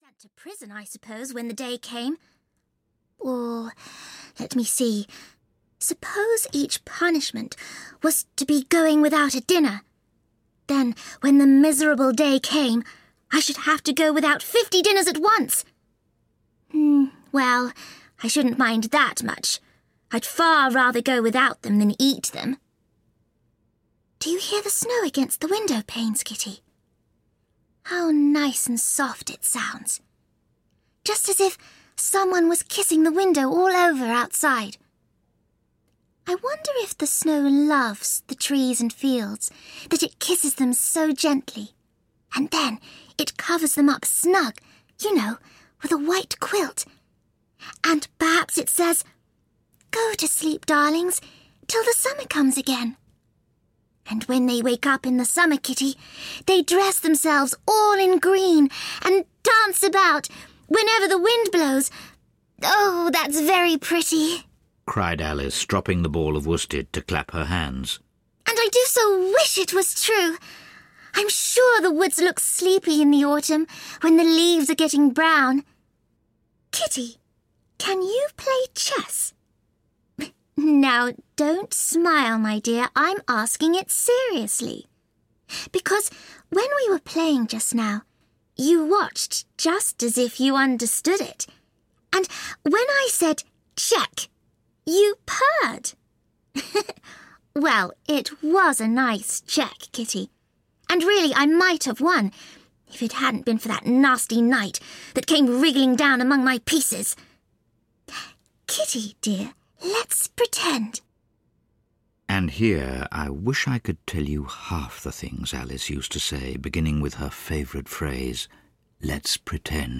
Through the Looking-Glass and What Alice Found There (EN) audiokniha
Ukázka z knihy